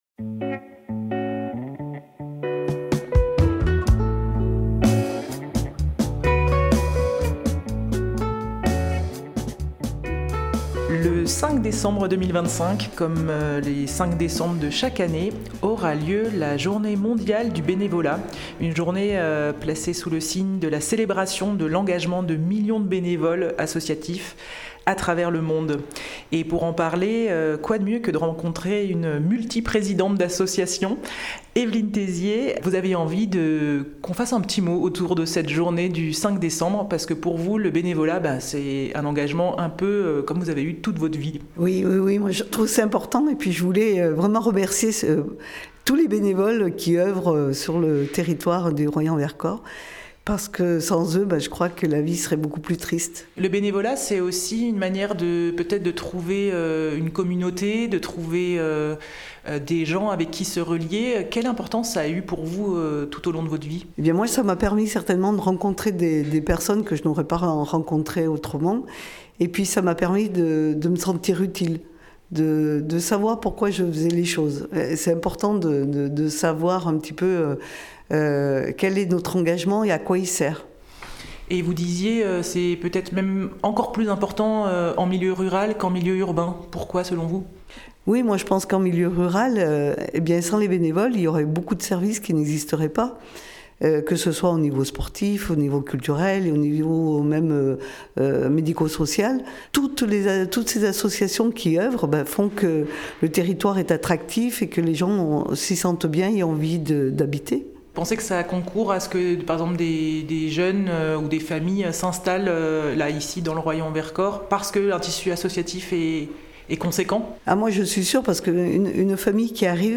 Son propos est complété par plusieurs autres témoignages de bénévoles du Royans et du Vercors.